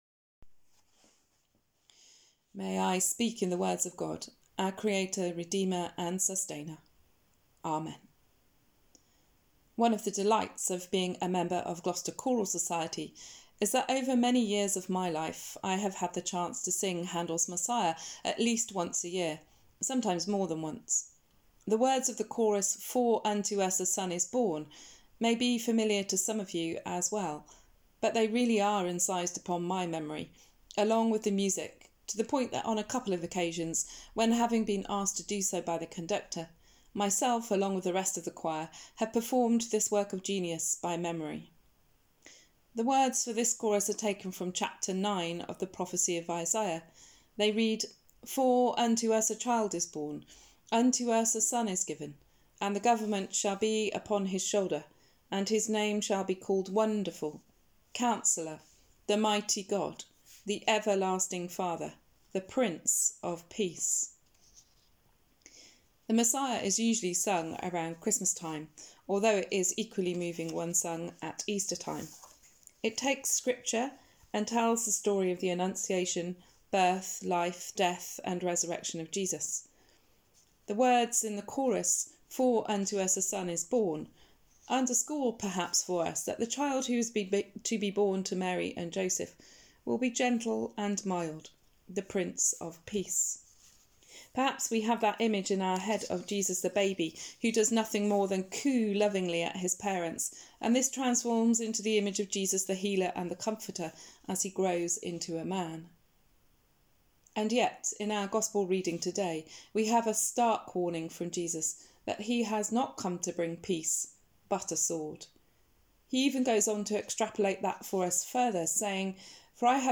Sermon: The Prince of Peace?